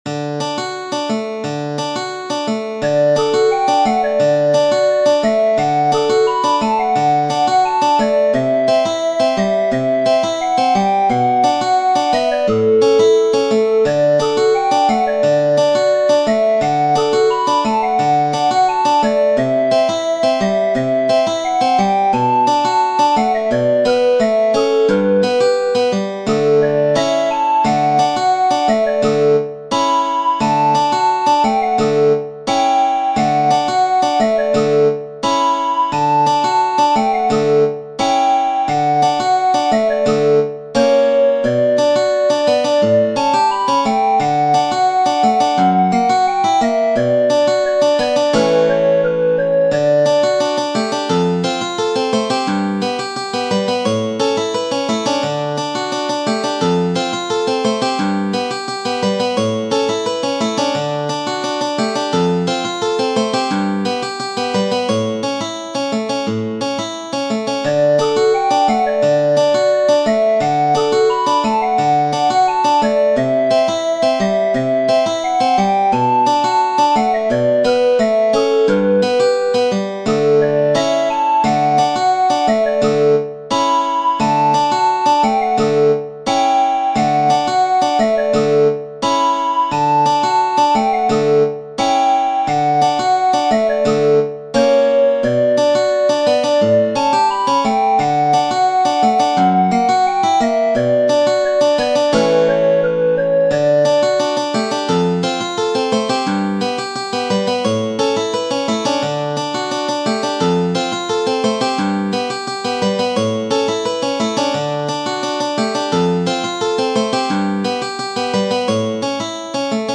Genere: Bambini